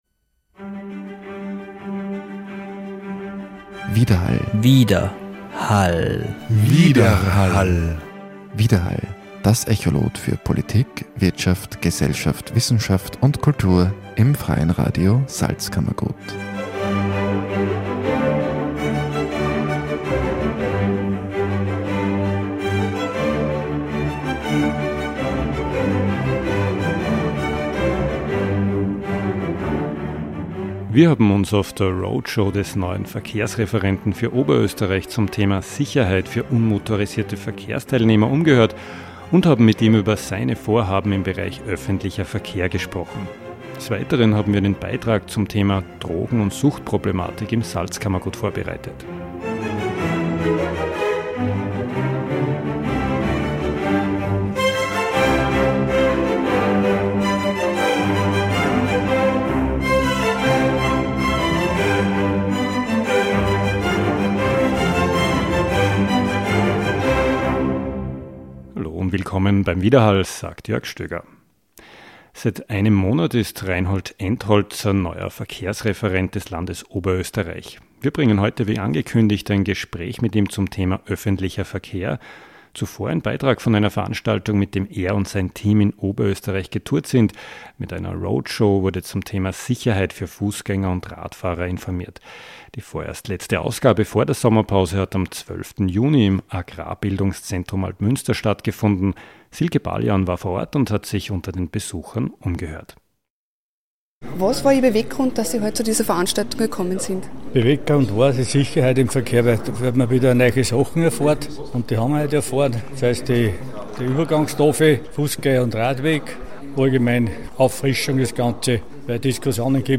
Verkehrsreferent Reinhold Entholzer im Gespräch